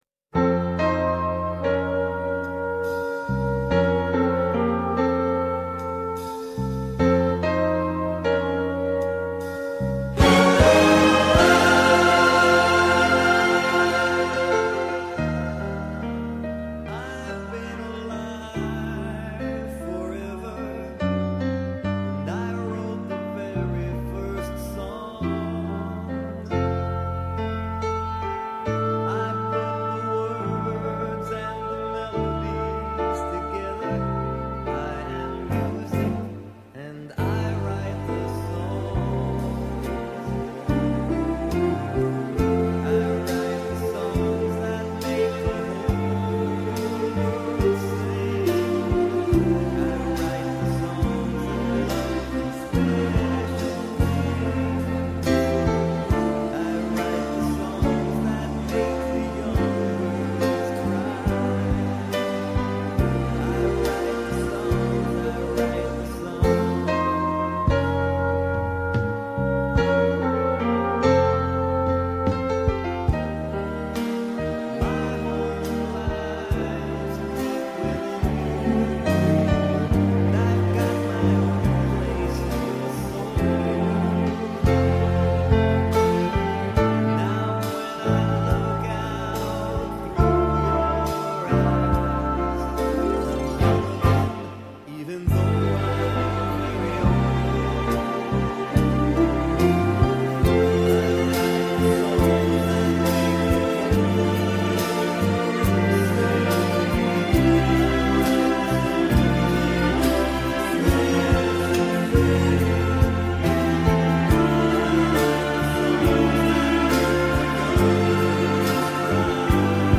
Karaoke Version